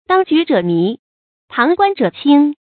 注音：ㄉㄤ ㄐㄨˊ ㄓㄜˇ ㄇㄧˊ ，ㄆㄤˊ ㄍㄨㄢ ㄓㄜˇ ㄑㄧㄥ
當局者迷，旁觀者清的讀法